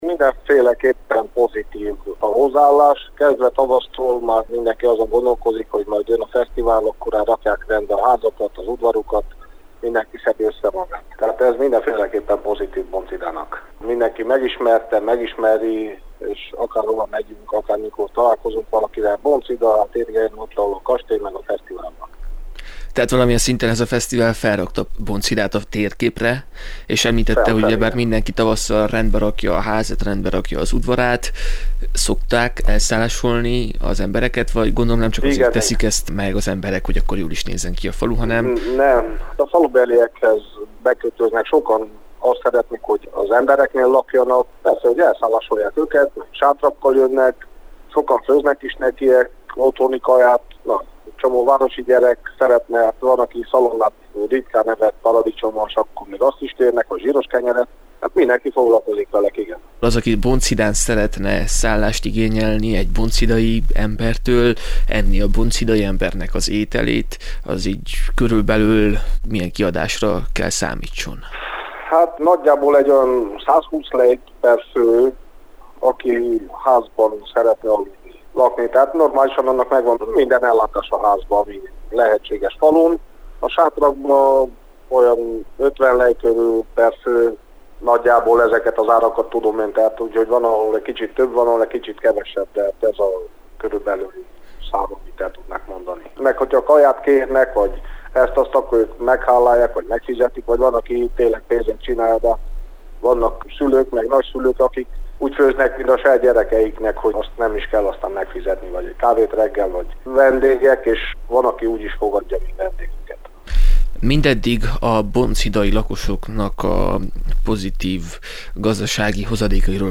A község lakói már tavasszal elkezdik rendbe tenni házaikat és udvaraikat – nyilatkozta rádiónknak Grúz Miklós István, Bonchida alpolgármestere.